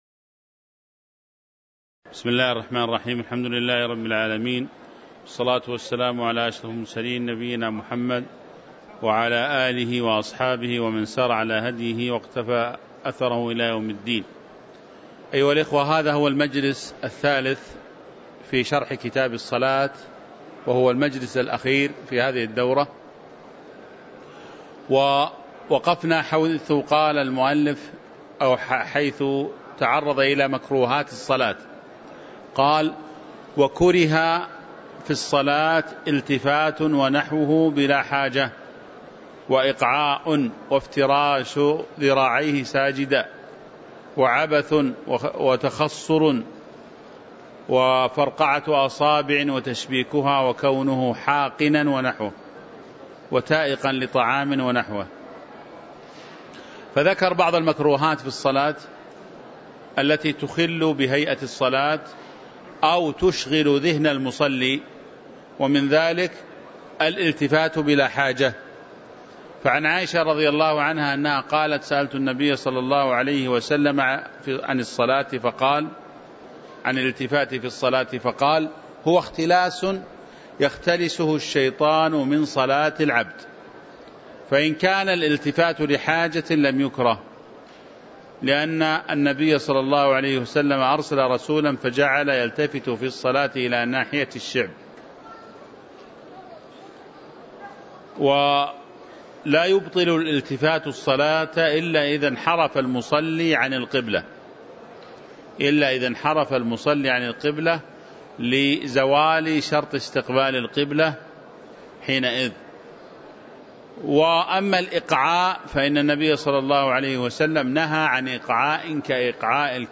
تاريخ النشر ٢٢ جمادى الآخرة ١٤٤٥ هـ المكان: المسجد النبوي الشيخ